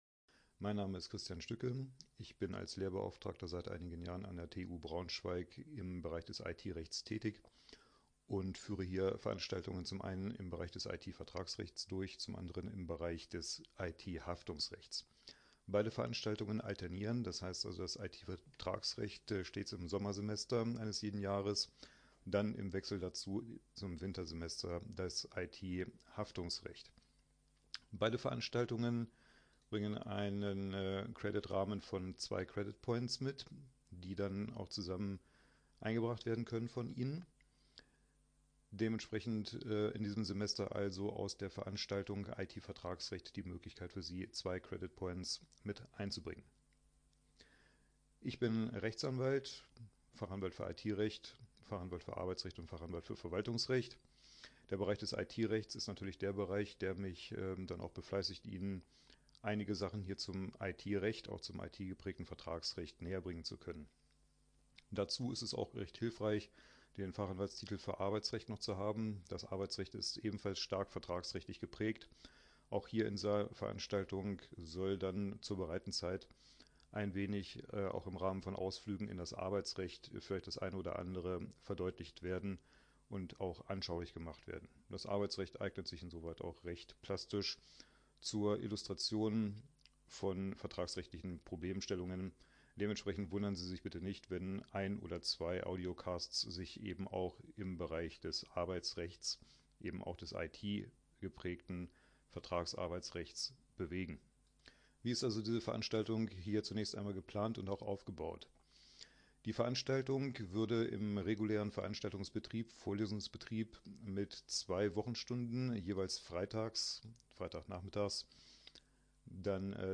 Vorlesung IT-Recht — TU Braunschweig